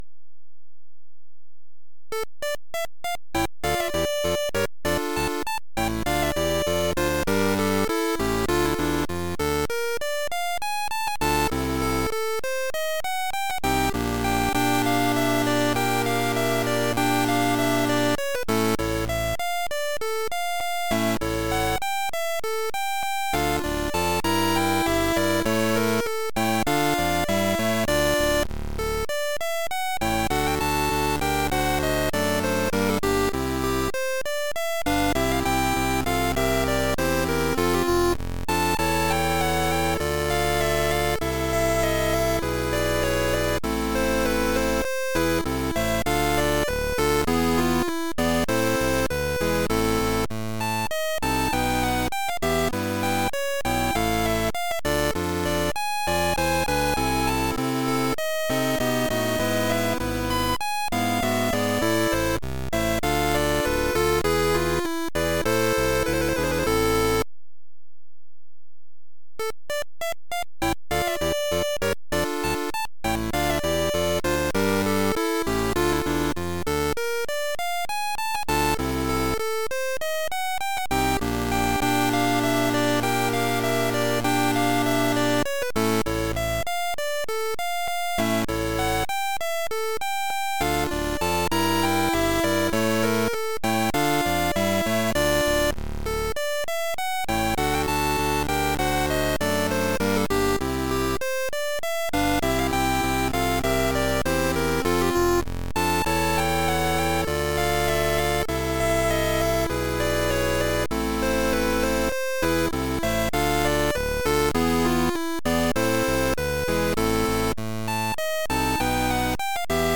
giving them a distinct video game feel.
background music